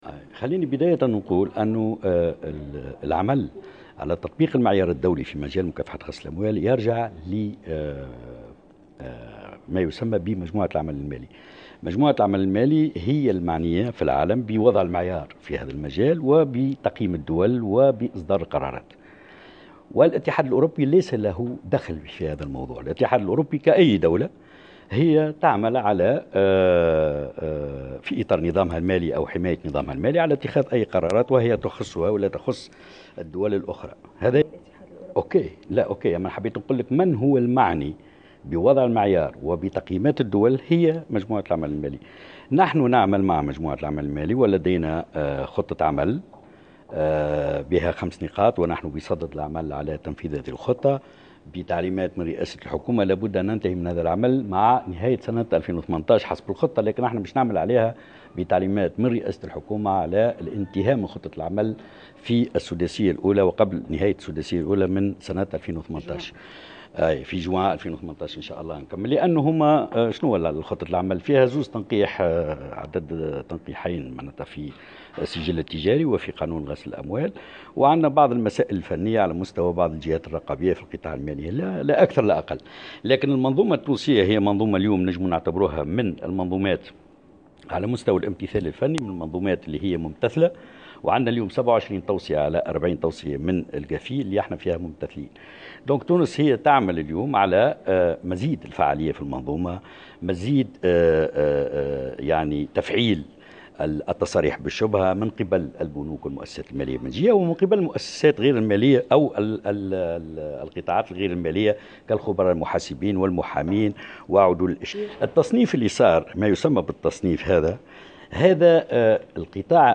في تصريح لمراسلة الجوهرة "اف ام"